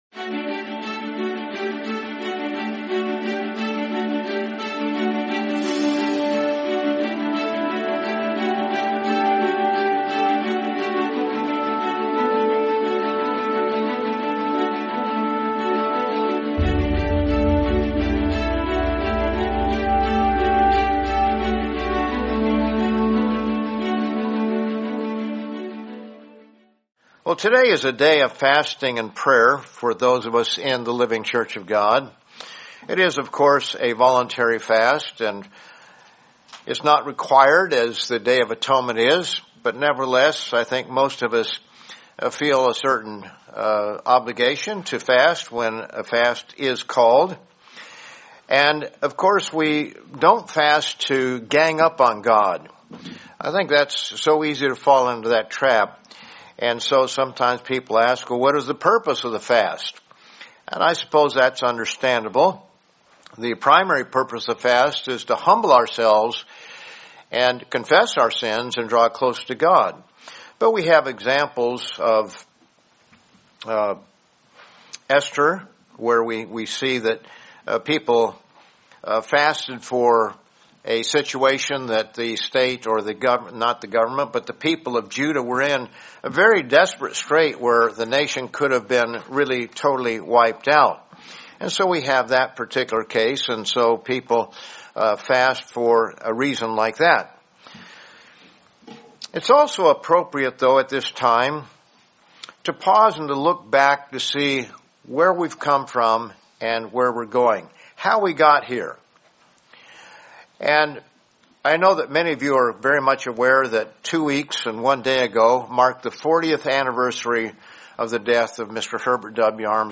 Sermon Mr. Armstrong's Legacy: 40 Years Later